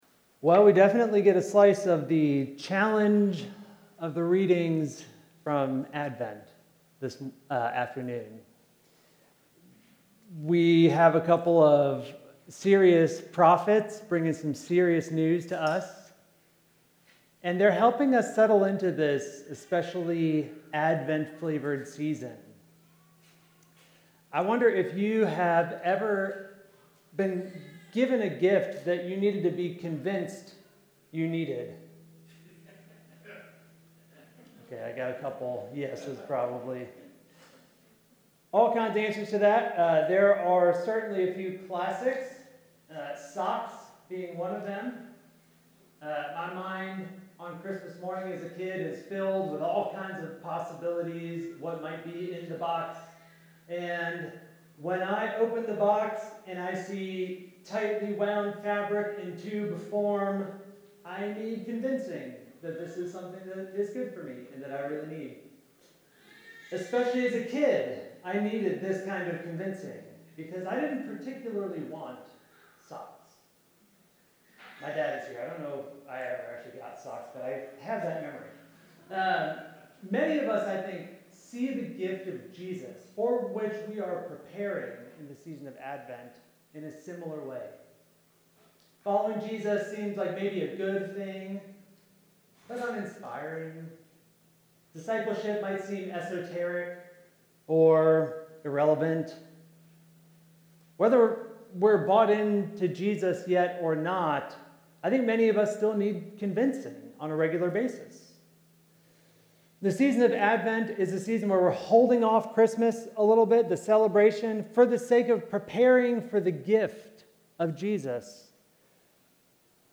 message from the Second Sunday of Advent